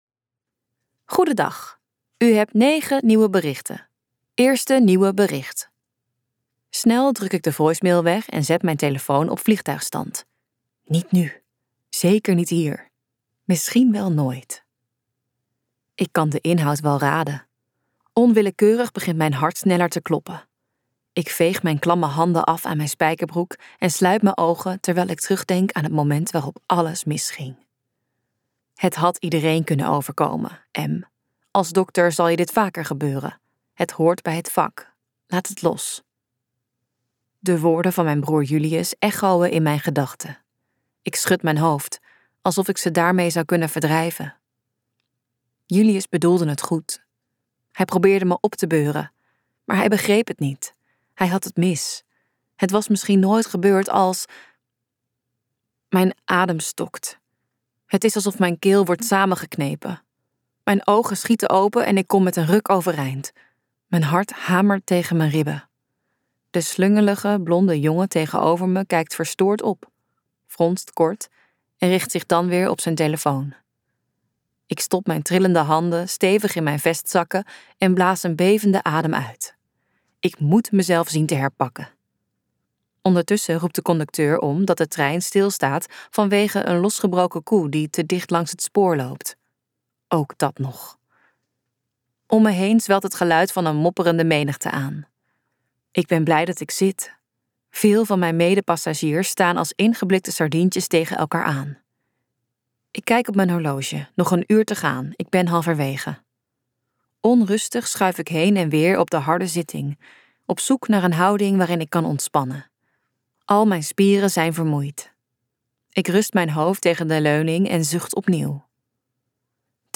Ambo|Anthos uitgevers - Operatie liefde luisterboek